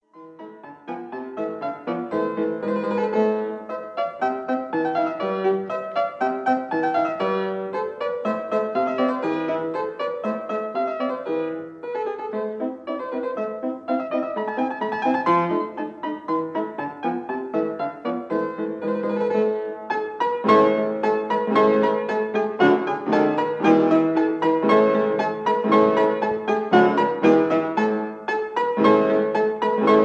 fortepiano